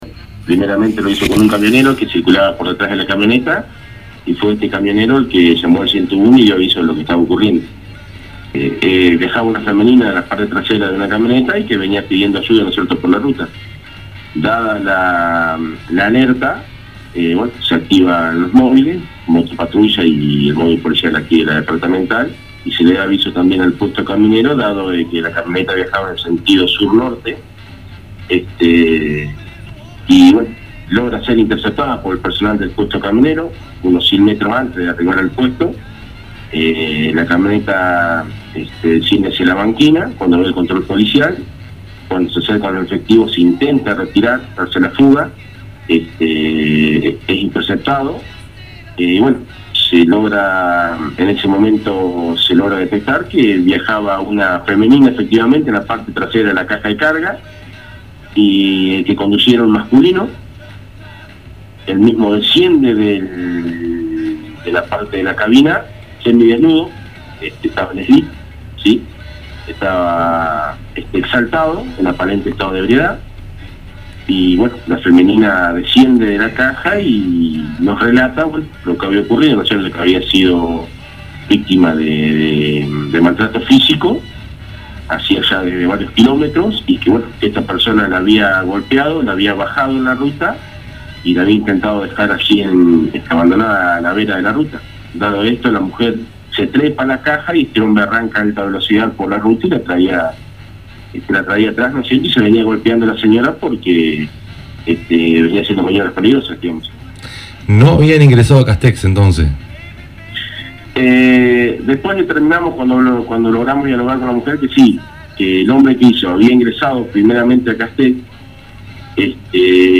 dialogó con el programa radial «CASTEX 24 A FULL» por la 94.9